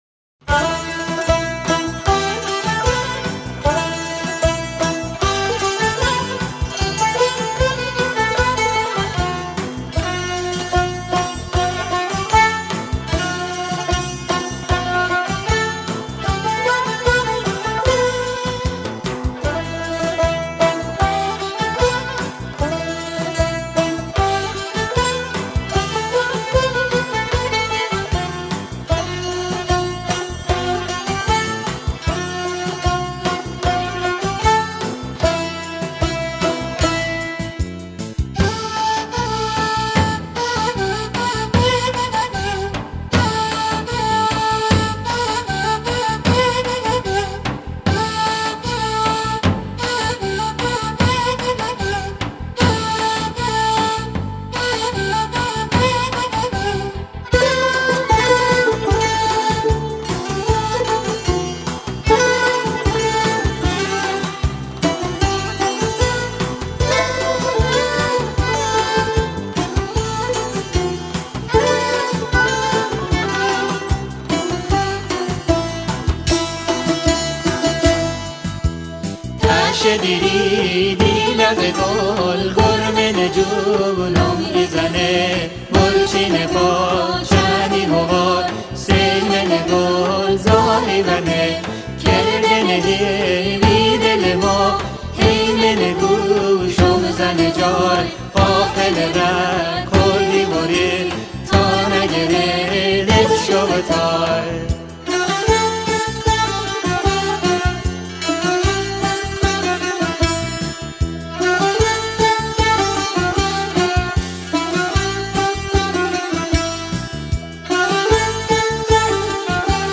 آهنگ بختیاری آهنگ شاد بختیاری